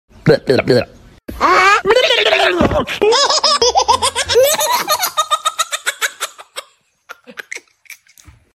Cute Mexica Plush Dancing Cactus